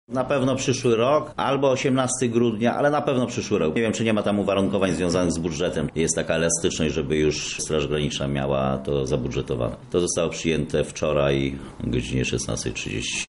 O szczegółach mówi Włodzimierz Karpiński minister skarbu państwa.